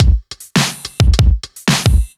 OTG_Kit7_Wonk_110a.wav